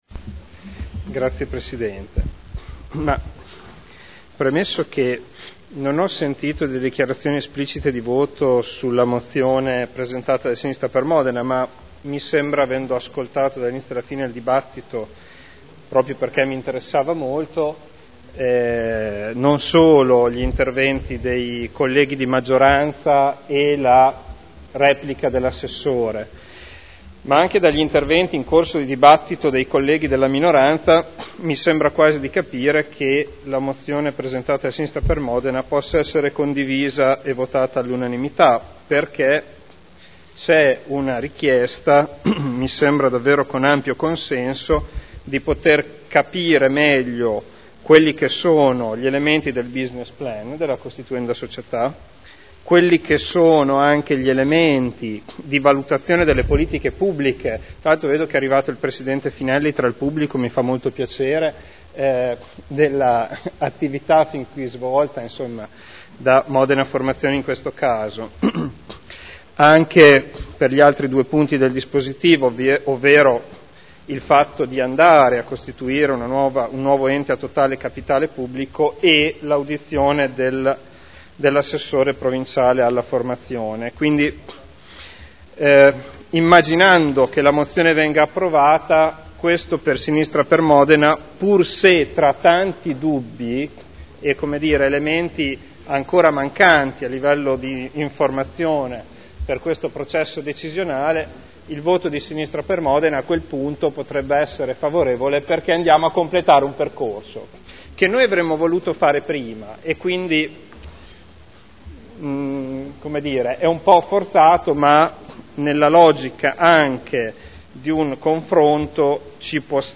Seduta del 16 aprile. Proposta di deliberazione: Unificazione delle società pubbliche di formazione professionale dell’area modenese. Dichiarazioni di voto